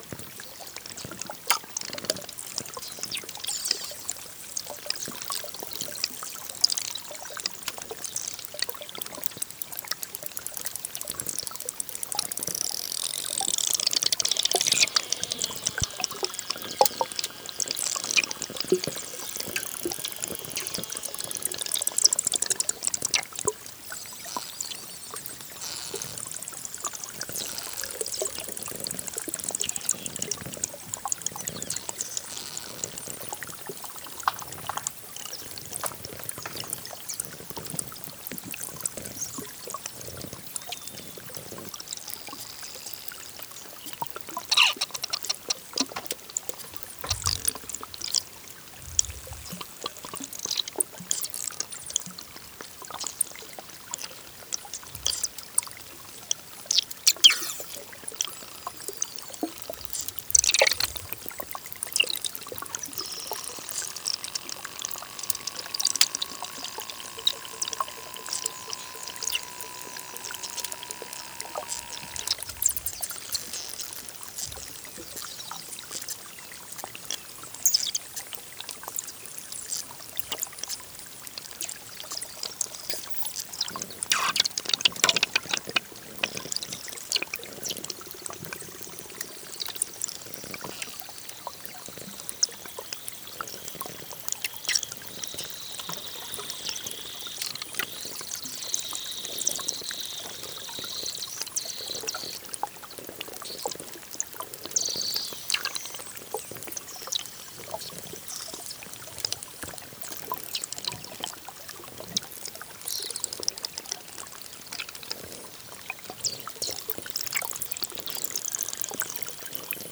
Randonnée et expérience sonore glaciaire
Une invitation à écouter les sons du glacier de Moiry
La naissance et les rotations d’iceberg alpins sont des grands moments d’écoute. On entends des éclats, cassures fines, frottages, gémissements.